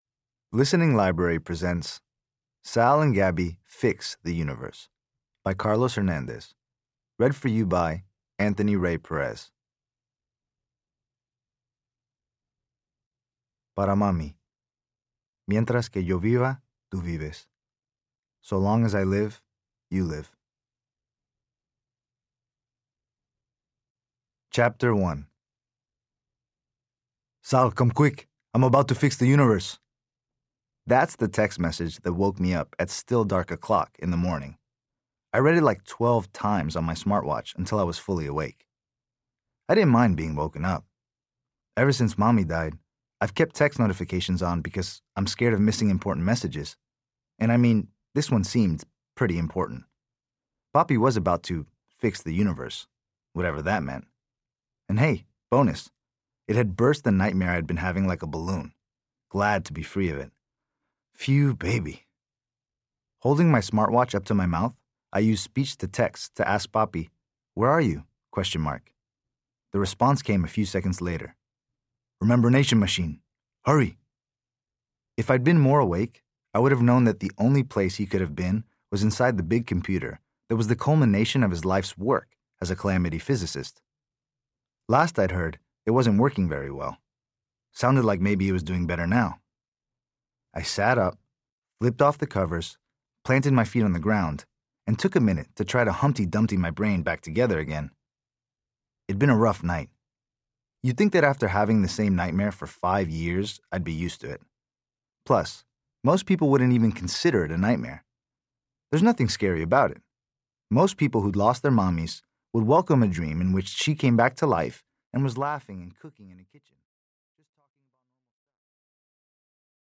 Spoken word.
Children's audiobooks.